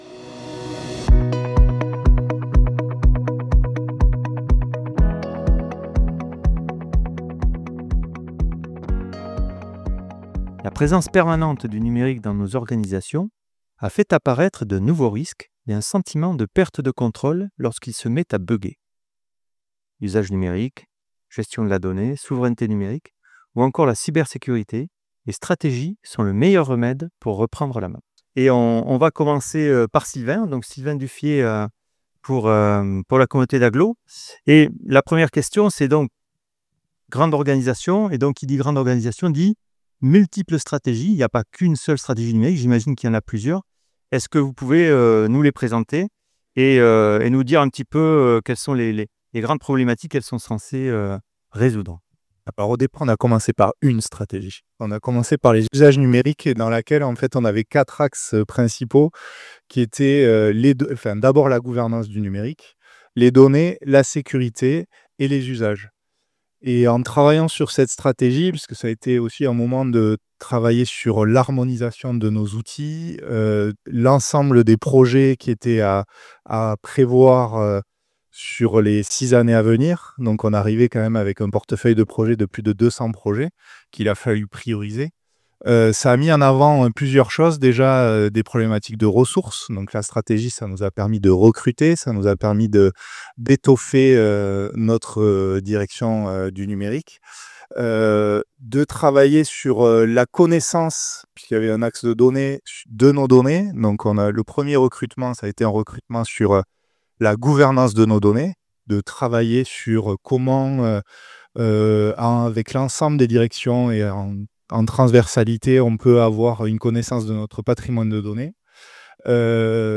Table Ronde 2025 - Maîtriser ses usages numériques
6hRX5xON9tHMo8MCyJ1ZG_Table ronde.mp3